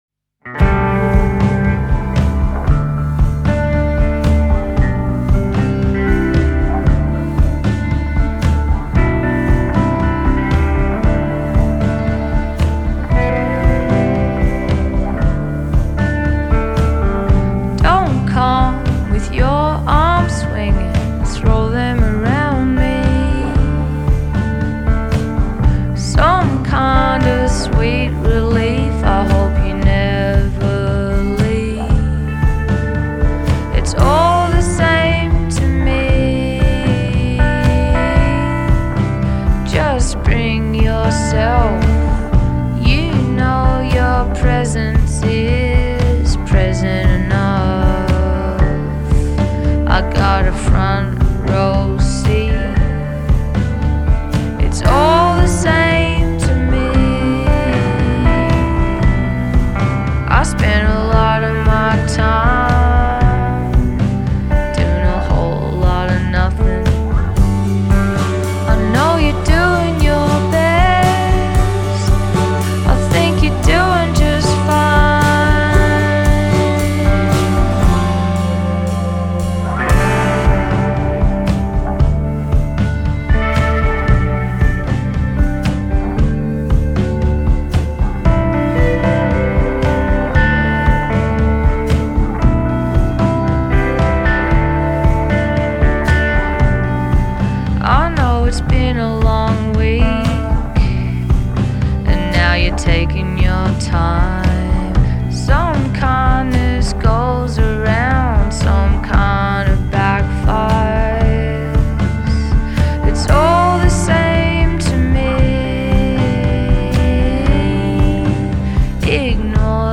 a gentle slow burn that really builds by the end…